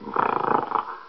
sounds_gorilla_snarl_01.ogg